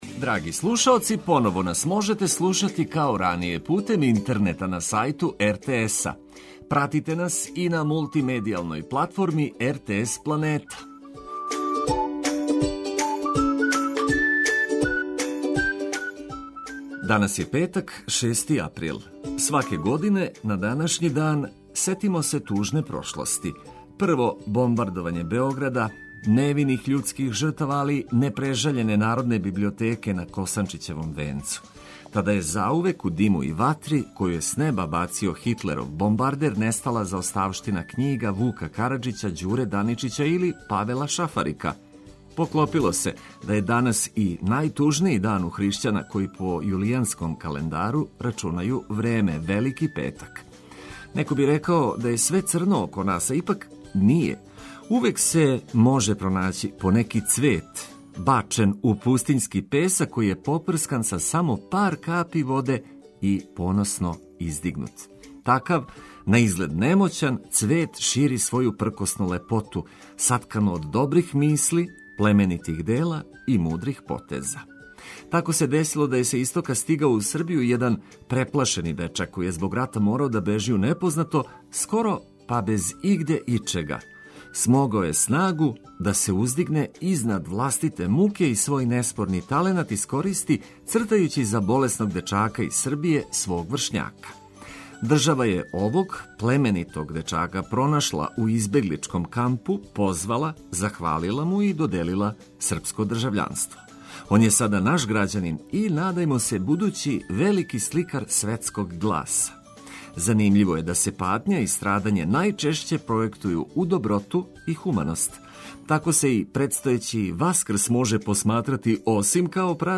Током овог тужног јутра када се сећамо Христовог страдања, емитоваћемо све важне информације уз пригодну музику.